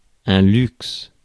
Ääntäminen
IPA : /ˈlʌk.ʃə.ɹi/ US : IPA : /ˈlʌɡ.ʒə.ɹi/